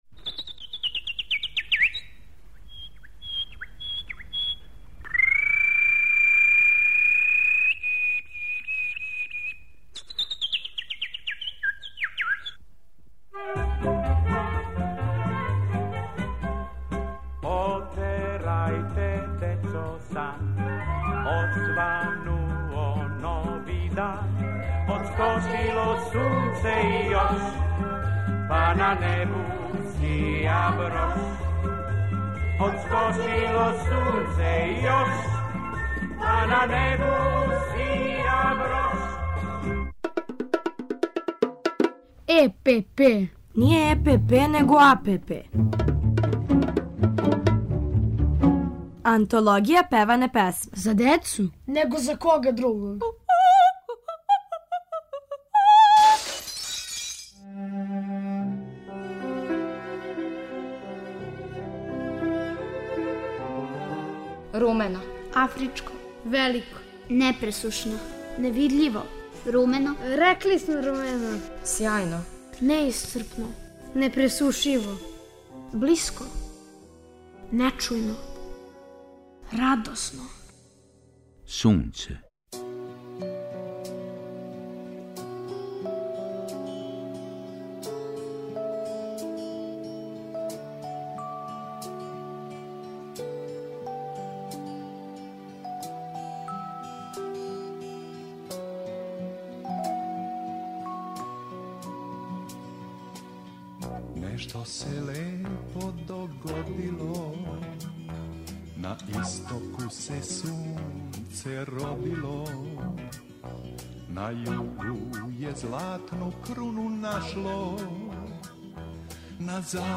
У серијалу Антологија певане песме певамо и размишљамо о Сунцу, уз Колибри, Драгана Лаковића, Дечију драмску групу Радио Београда, а ту је и Иво Андрић.